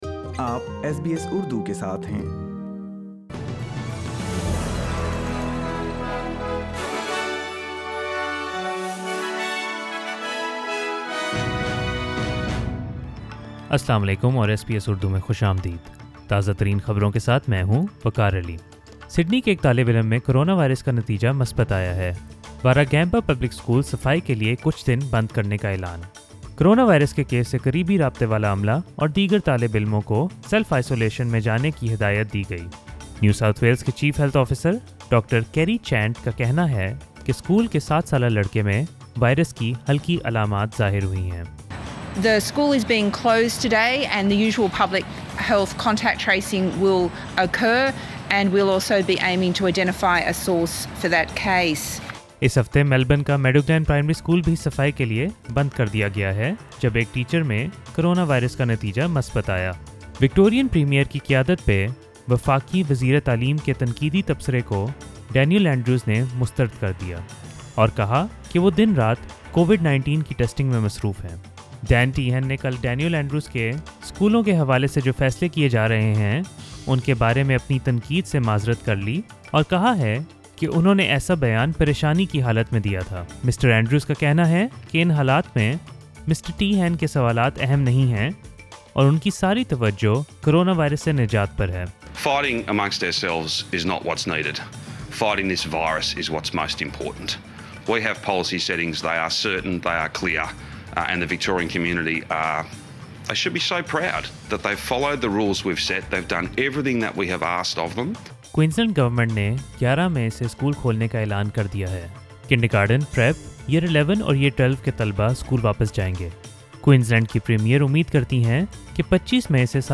SBS Urdu News 4th May 2020